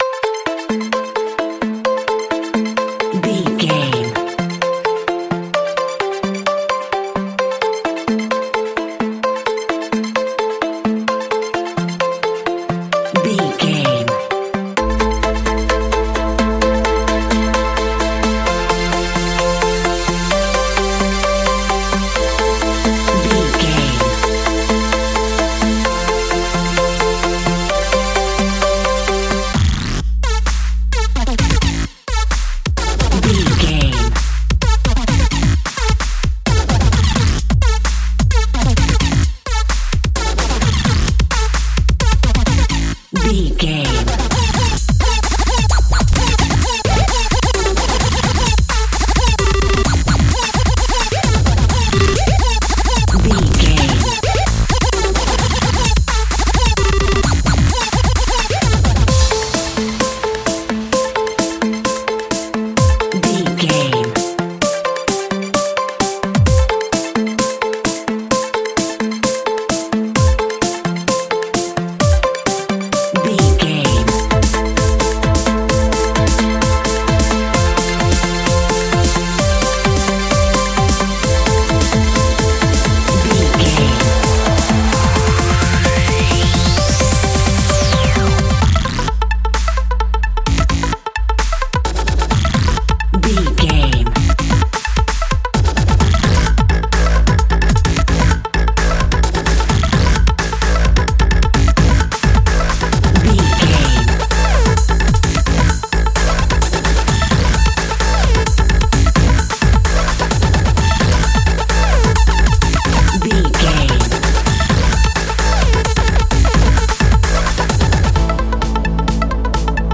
Melodic Dubstep Fusion Trance.
Aeolian/Minor
Fast
aggressive
powerful
dark
driving
energetic
futuristic
hypnotic
industrial
drum machine
synthesiser
breakbeat
synth leads
synth bass